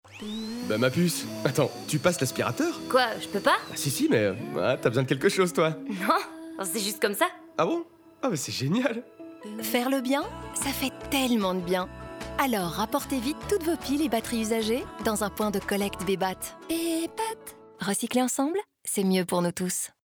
Sound Production & Sound Design: La Vita Studios
250324-Bebat-radio-mix-OLA--23LUFS-aspirateur-FR-20.mp3